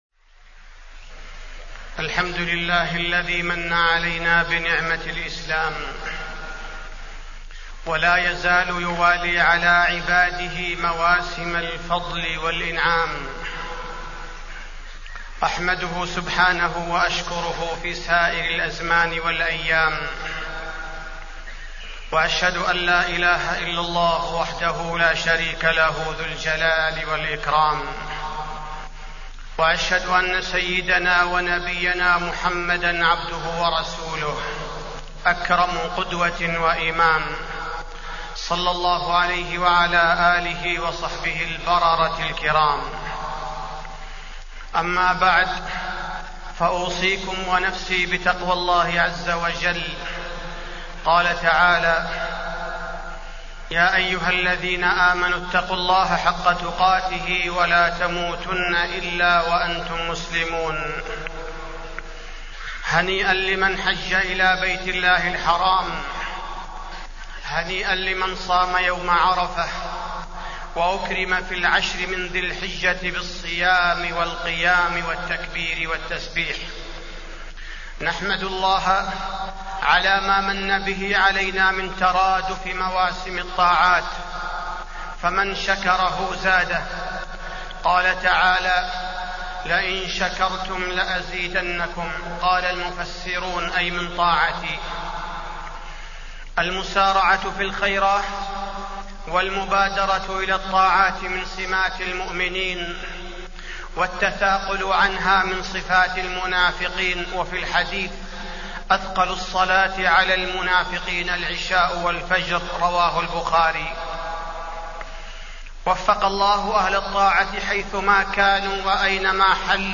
تاريخ النشر ١١ ذو الحجة ١٤٢٨ هـ المكان: المسجد النبوي الشيخ: فضيلة الشيخ عبدالباري الثبيتي فضيلة الشيخ عبدالباري الثبيتي طاعة الله والحج